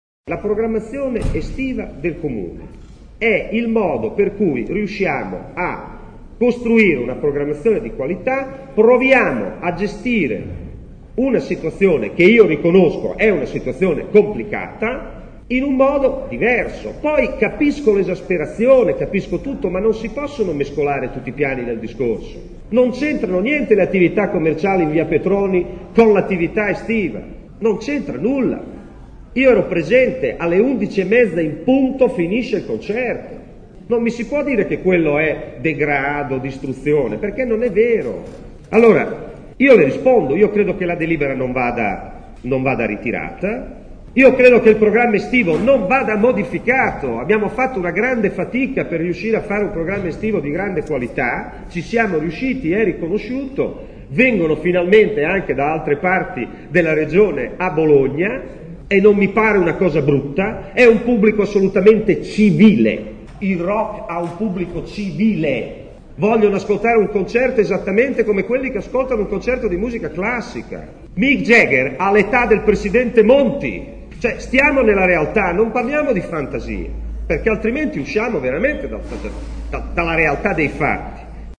L’assessore alla cultura è intervenuto al question time: non cambierà né la delibera, né il programma di Bé, che a suo avviso è di grande qualità. Ronchi ha risposto ad una domanda di attualità del consigliere Pdl Lorenzo Tomassini.